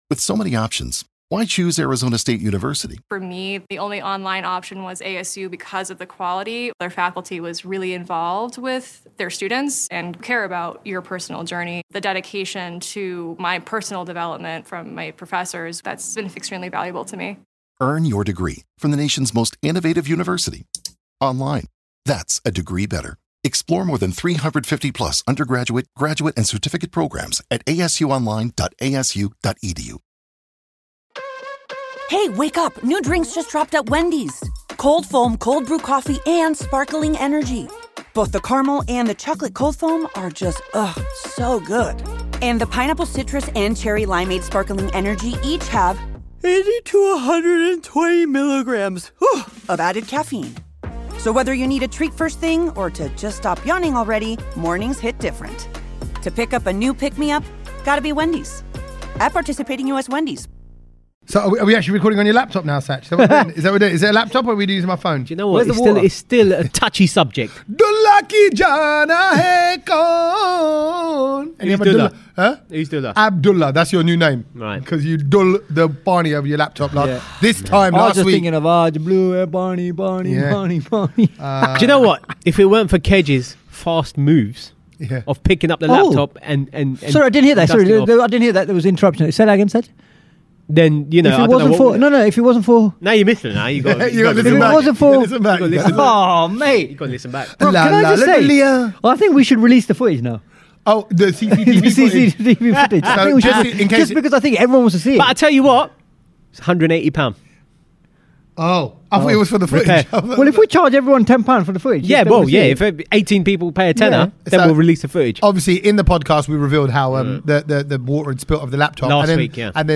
at Kanchans restaurant in Gants Hill, Ilford for hosting us this week!